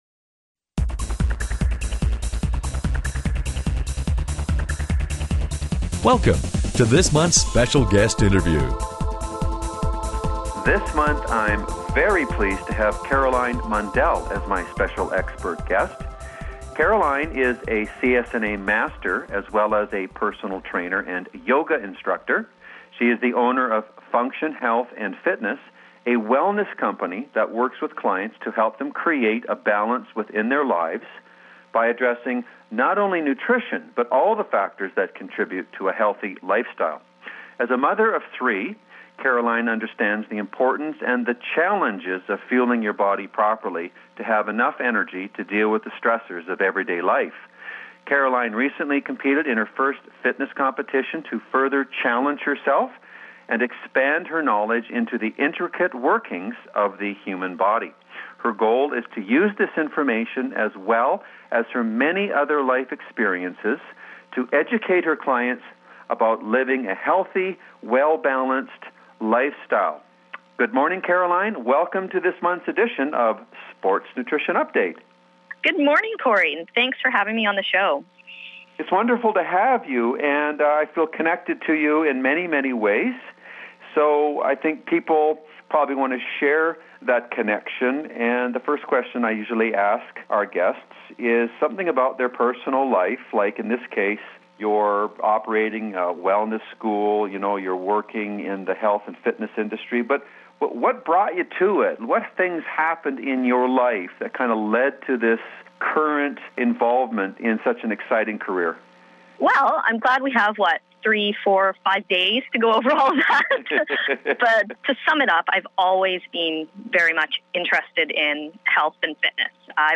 Special Guest Interview Volume 11 Number 9 V11N9c